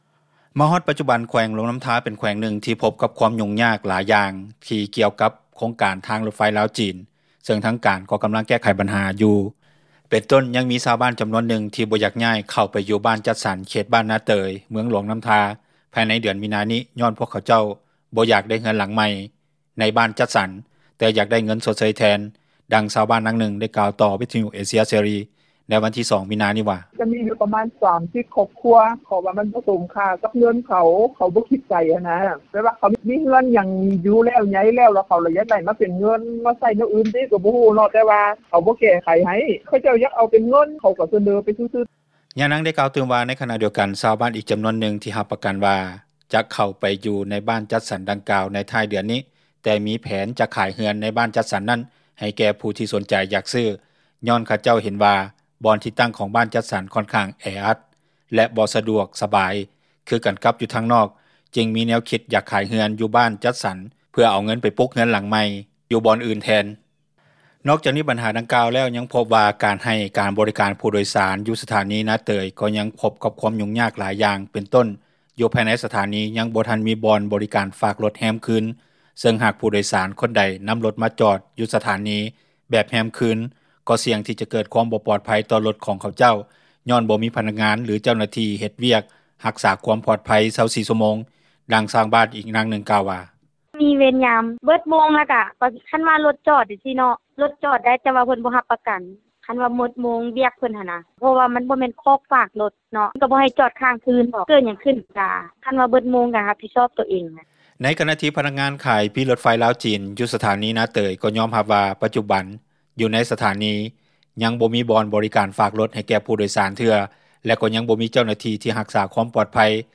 ດັ່ງຊາວບ້ານນາງນຶ່ງກ່າວຕໍ່ ວິທຍຸເອເຊັຽເສຣີ ໃນວັນທີ 02 ມີນາ ນີ້ວ່າ:
ດັ່ງຊາວບ້ານ ອີກນາງນຶ່ງກ່າວວ່າ: